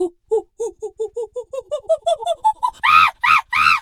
monkey_2_chatter_scream_01.wav